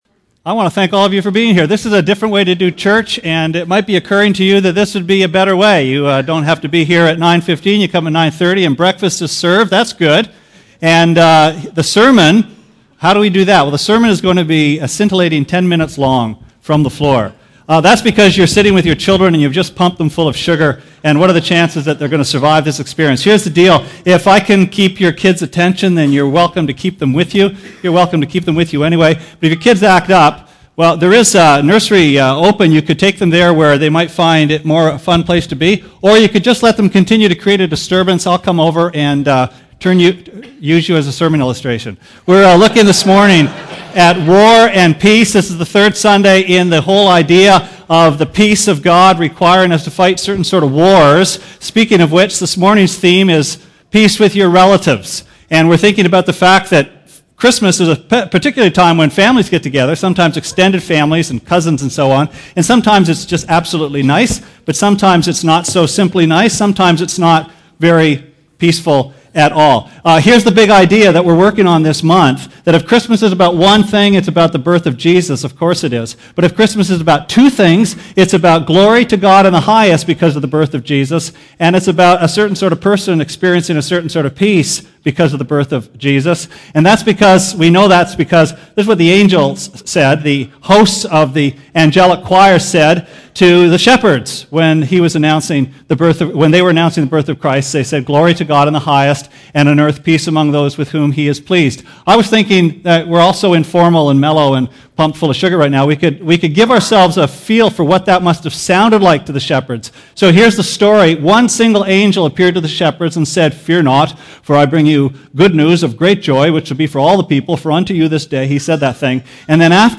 Sermon Archives - West London Alliance Church
It's Christmas Family Sunday!